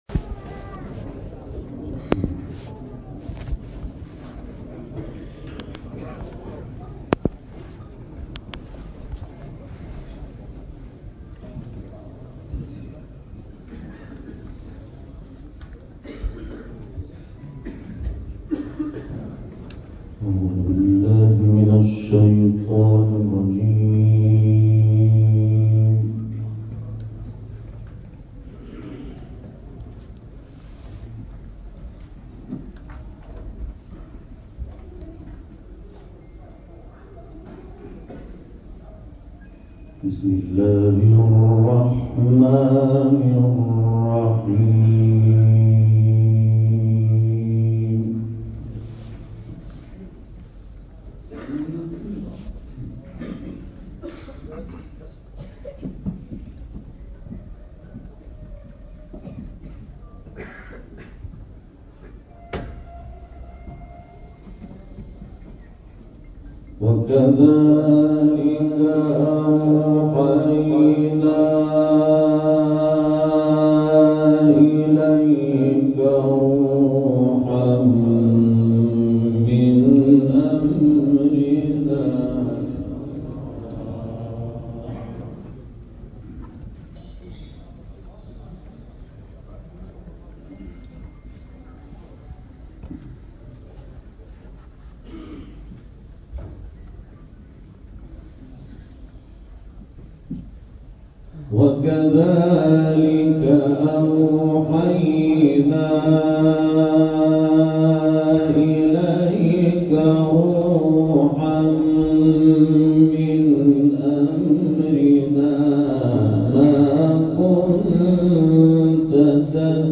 جدیدترین تلاوت «محمود شحات» در کویت
گروه شبکه اجتماعی: تلاوت سوری از قرآن کریم با صوت محمود شحات انور که به تازگی در کشور کویت اجرا شده است، می‌شنوید.